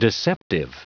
Prononciation du mot deceptive en anglais (fichier audio)
Prononciation du mot : deceptive